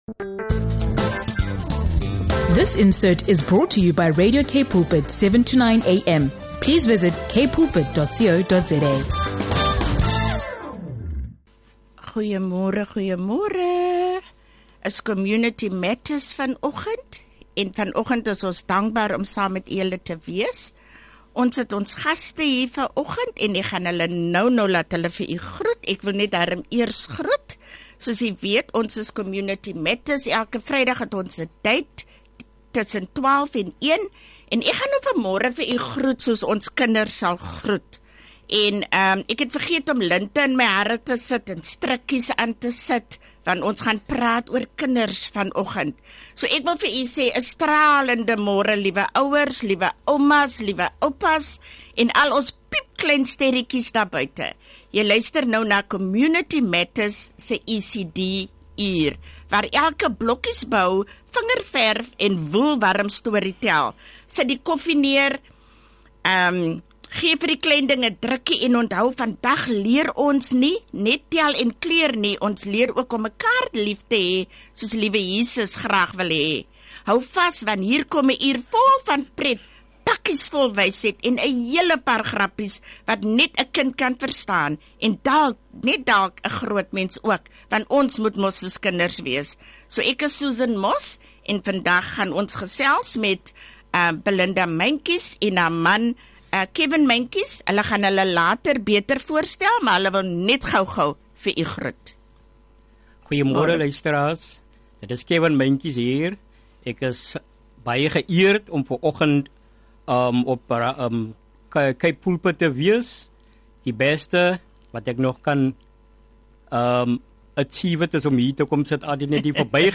From sharing heartfelt stories to highlighting a book giveaway competition for young readers aged 4–7, the episode emphasizes connection, encouragement, and practical ways families can engage in the community. Tune in for inspiring conversations, music, and insights that bring faith, learning, and fun together for the whole family.